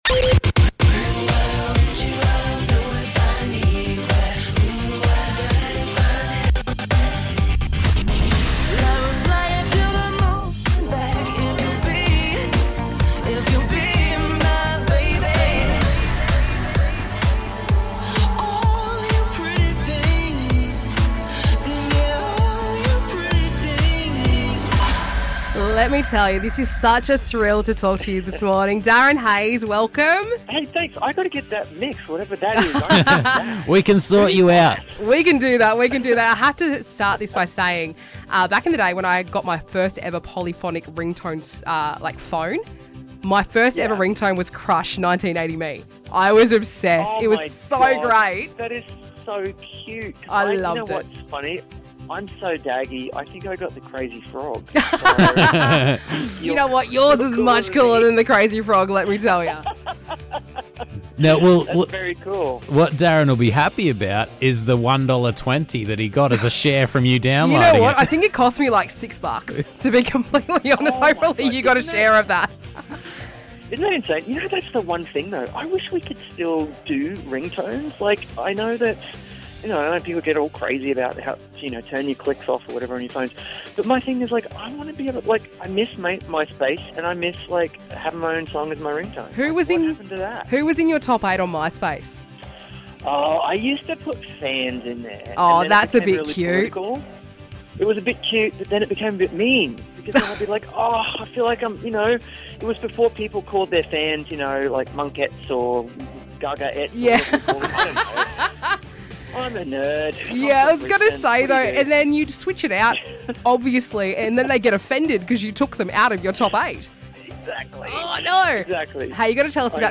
Interview with Darren Hayes!